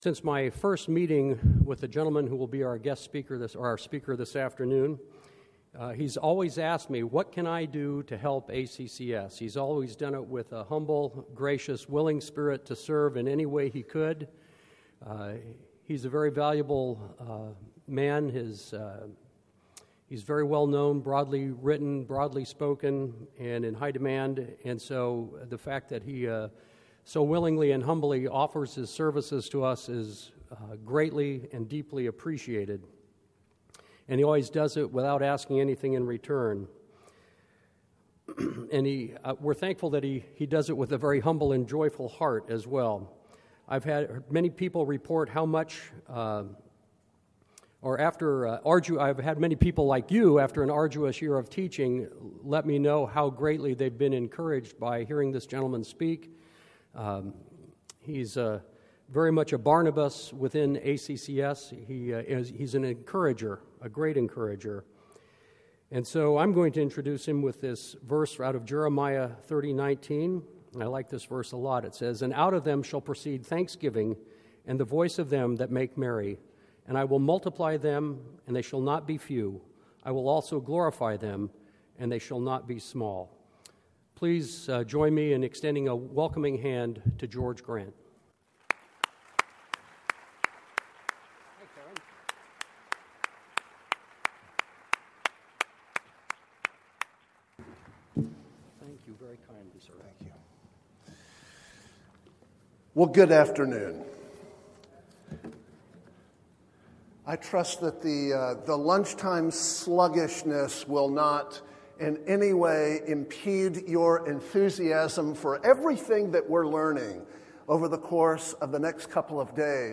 2012 Plenary Talk | 0:48:17 | All Grade Levels, History